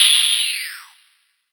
OPEN HAT 4.wav